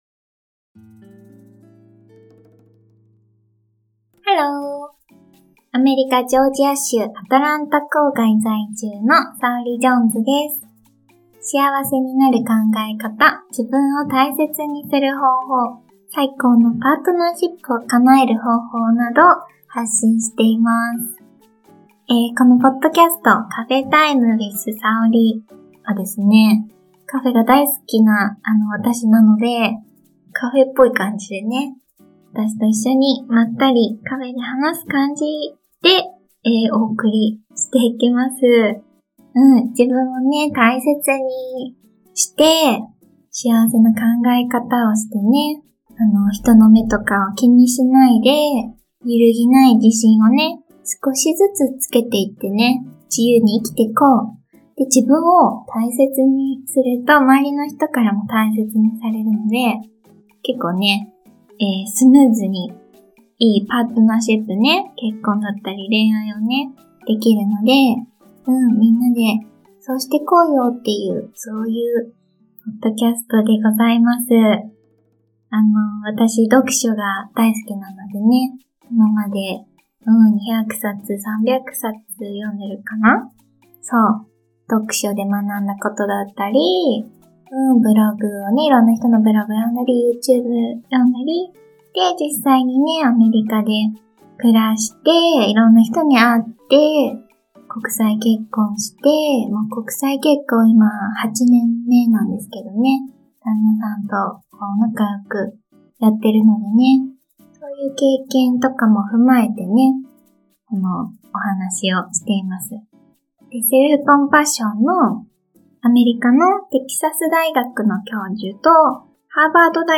【ゆるーく雑談回】あなたのオーラを明るくする方法
zatsudan-kai.mp3